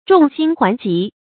眾星環極 注音： ㄓㄨㄙˋ ㄒㄧㄥ ㄏㄨㄢˊ ㄐㄧˊ 讀音讀法： 意思解釋： 比喻眾物圍繞一物或眾人擁戴一人。